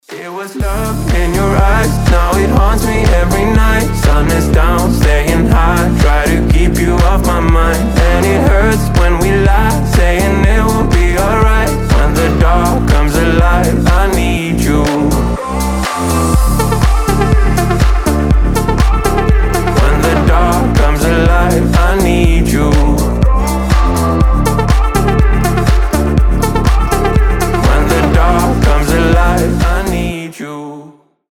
• Качество: 320, Stereo
EDM